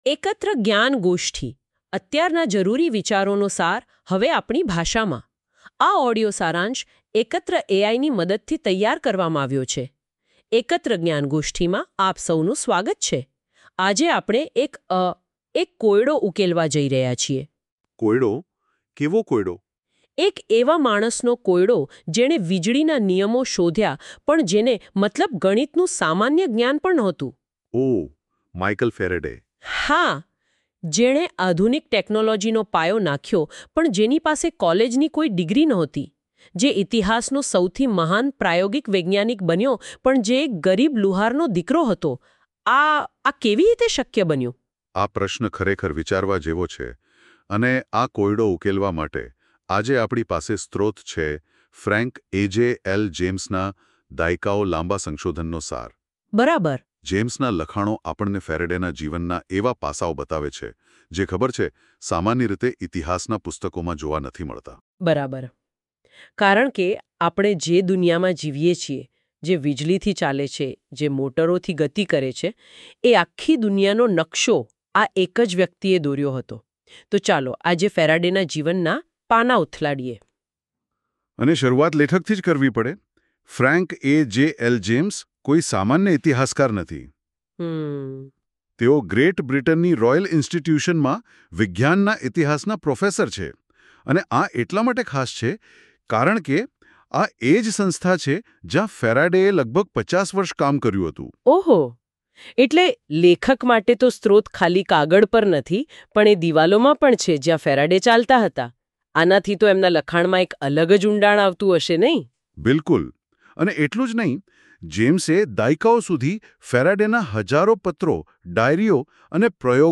Ekatra audio summary – Gujarati